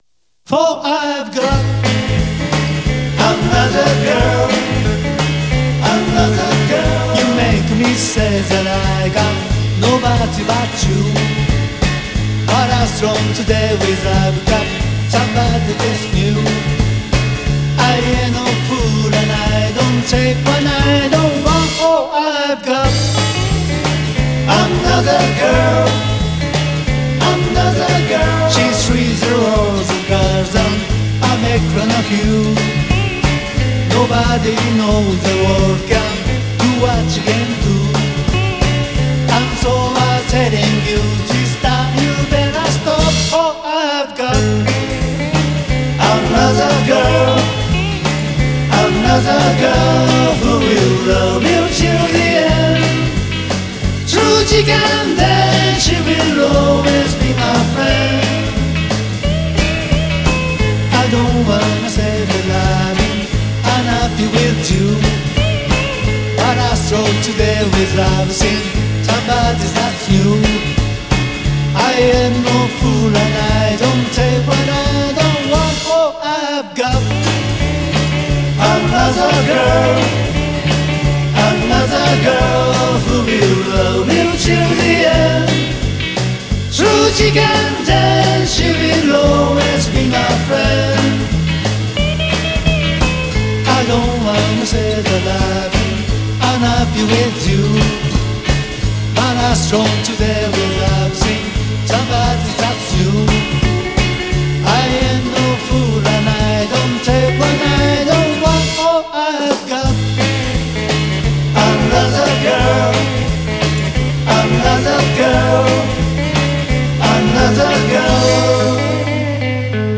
1st G ：Epiphone CASINO
2nd G ：Fender Telecaster
3rd G：Epiphone J-45 Elite
BassG：BARCLEY Hofner Model
MTR：Zoom MRS1266
Amp Simulater：Line6 POD2
私のカジノには付いてないので表現がイマイチだった事にしておきます（笑）